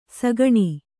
♪ sagaṇi